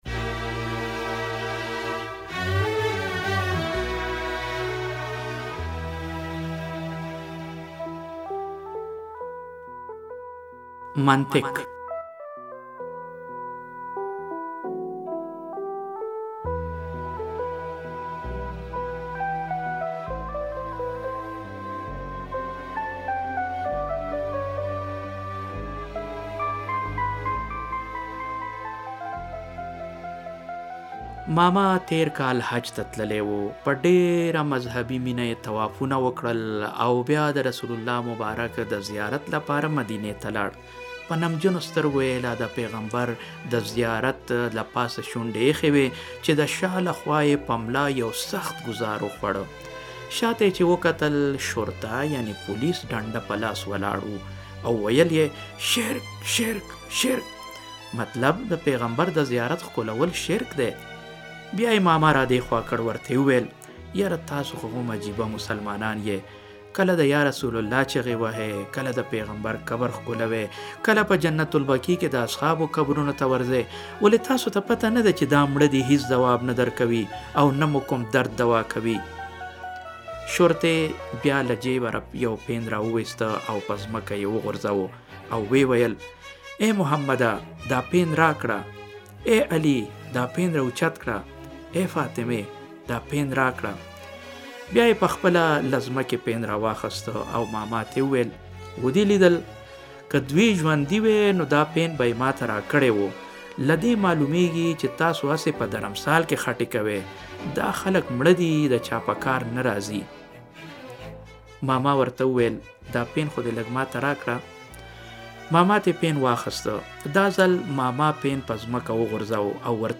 لنډه کیسه آیا دا شرک دی؟؟؟